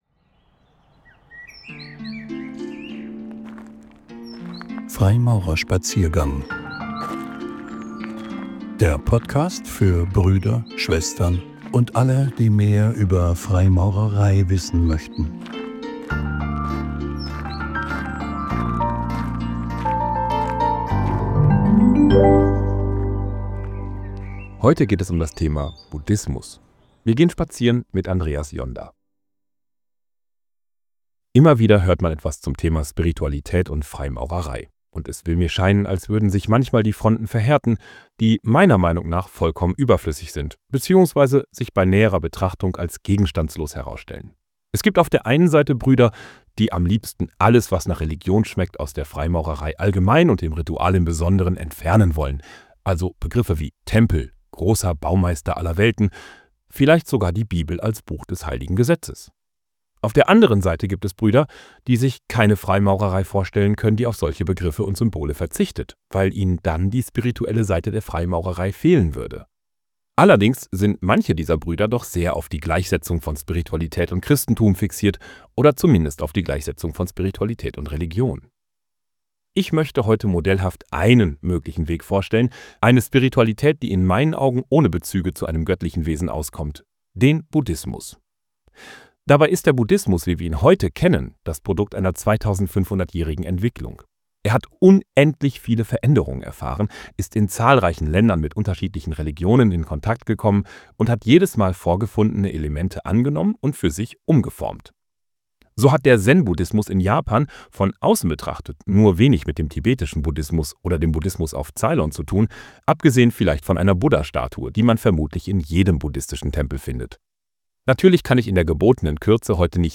Ein Vortrag ("Zeichnung")